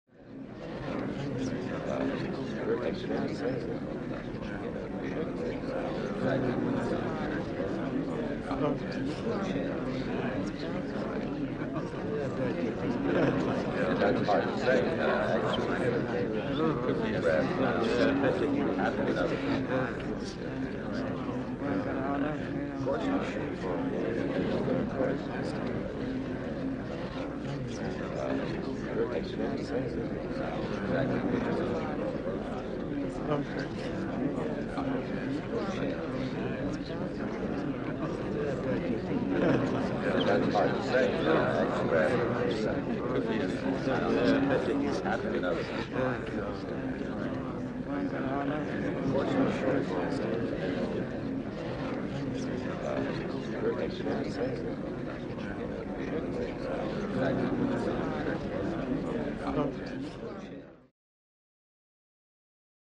Walla, Non Descript | Sneak On The Lot
Large Crowd Murmuring, Very Low Key With A Male Majority